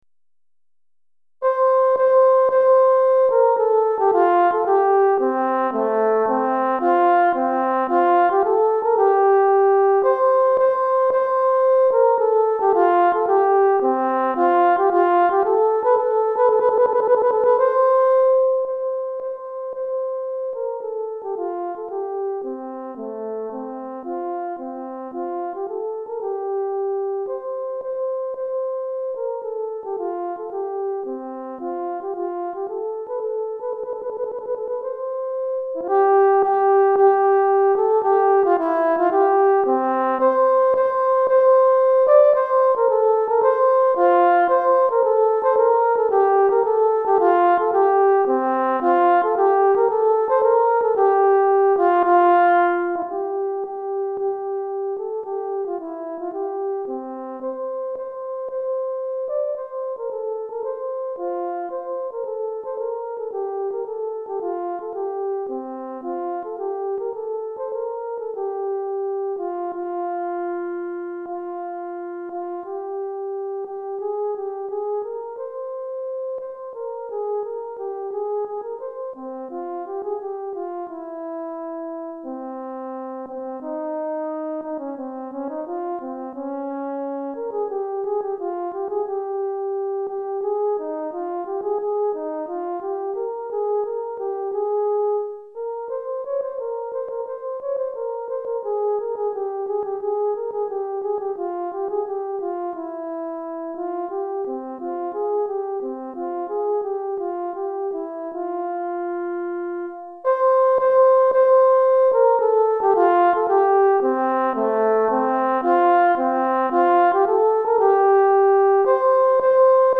Cor en Fa Solo